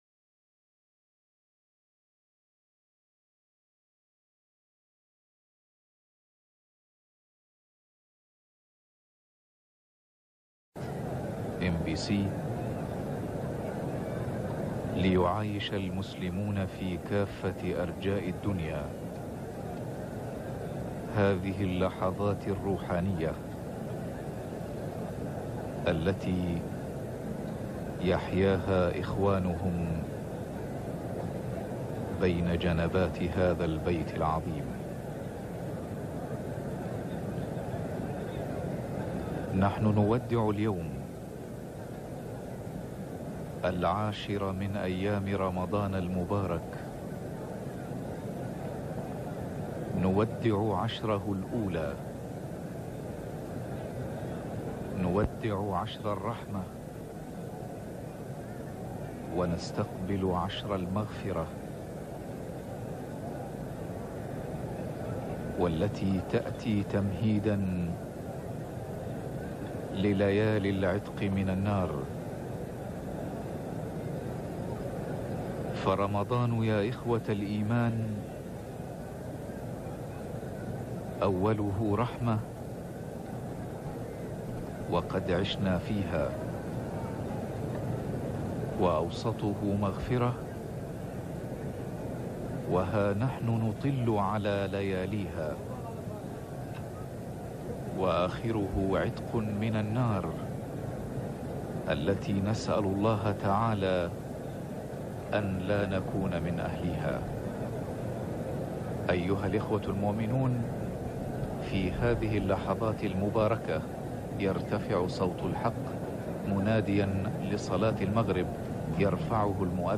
نوادر الأذان